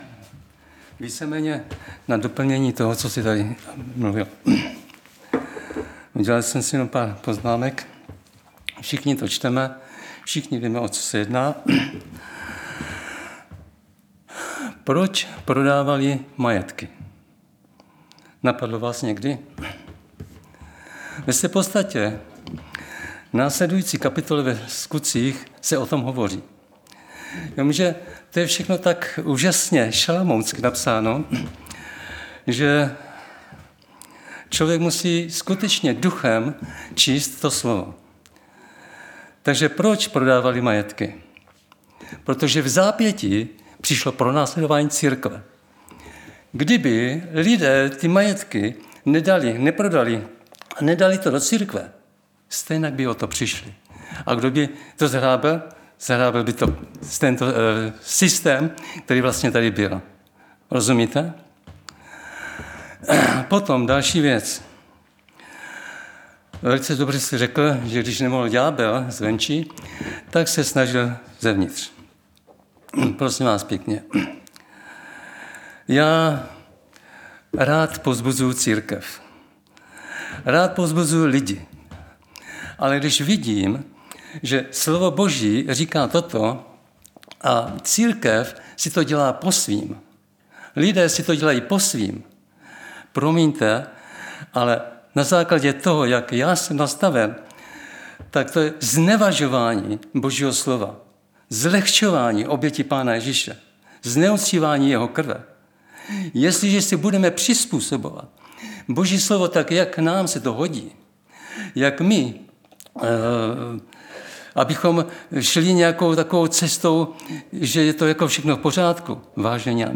Středeční vyučování
Záznamy z bohoslužeb